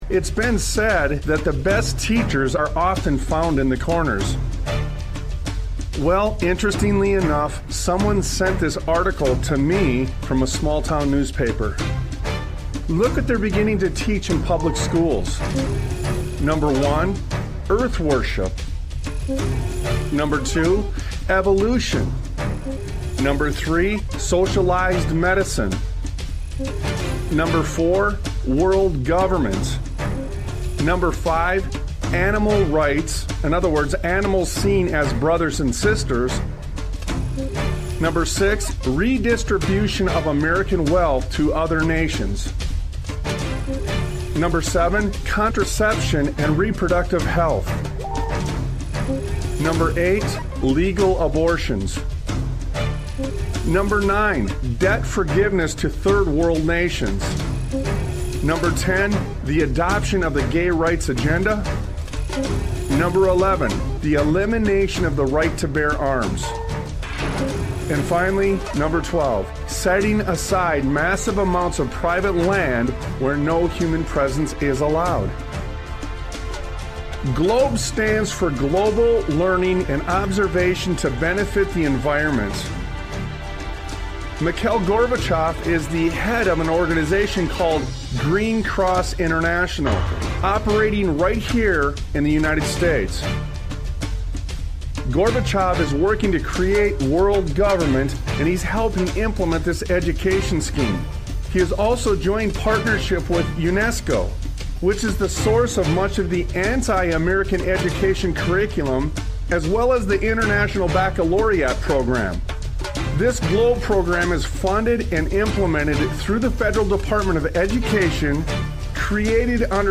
Talk Show Episode, Audio Podcast, Sons of Liberty Radio and Beyond The Trojan Horse on , show guests , about Beyond The Trojan Horse, categorized as Education,History,Military,News,Politics & Government,Religion,Christianity,Society and Culture,Theory & Conspiracy